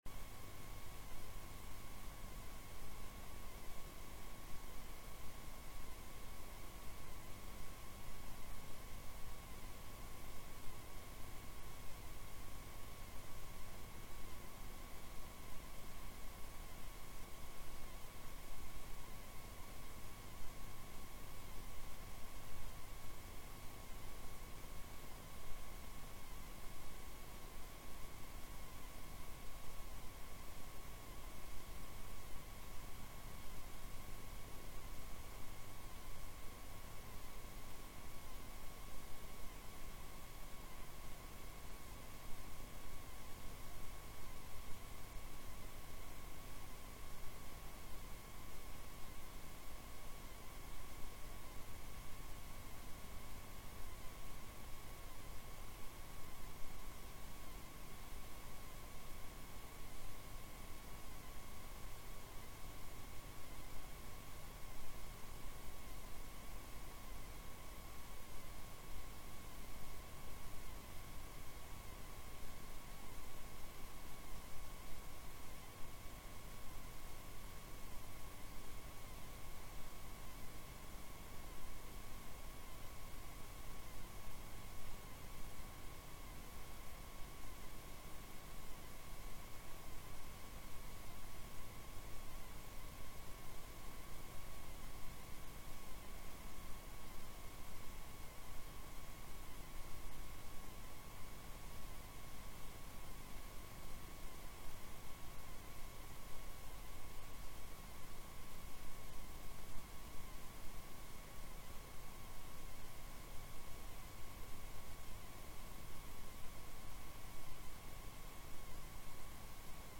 Rom: Lille Eureka, 1/3 Eureka